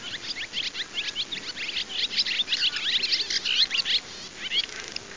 derevenskaya-lastochka-ili-kasatka-hirundo-rustica.mp3